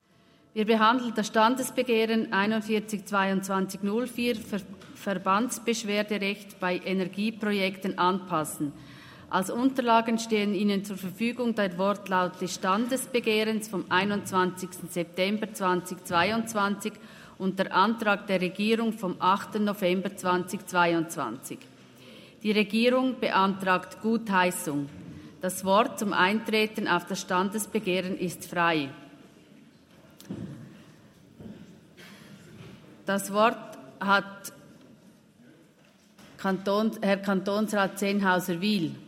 Session des Kantonsrates vom 18. bis 20. September 2023, Herbstsession
Dürr-Gams, Ratsvizepräsidentin: Die Regierung beantragt Gutheissung des Standesbegehrens.